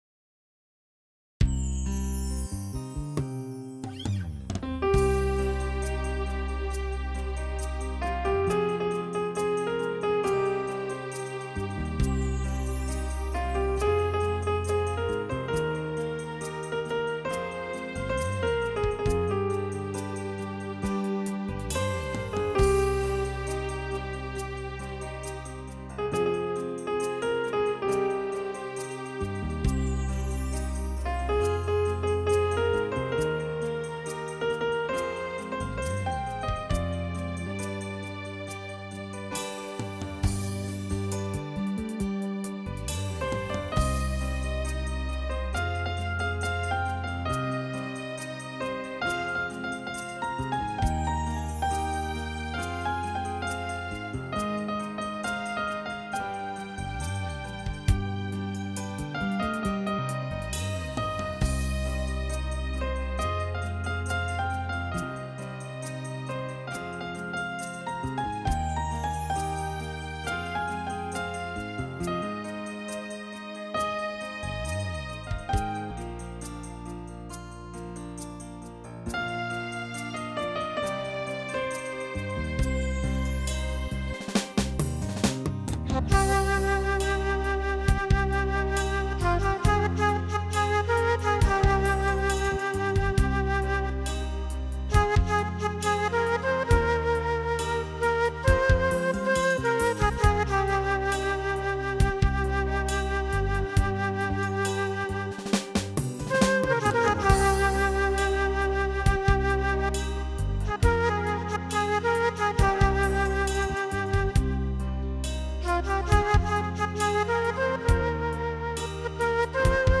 שיתוף - אשמח לשמוע את חוות דעתכם על הנגינה
האמת, בחרת שיר טיפ טיפה מורכב, הן מבחינת האוקרדים שלו שיש הרבה תזוזה, והן מבחינת הקצב.